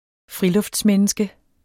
Udtale [ ˈfʁilɔfds- ]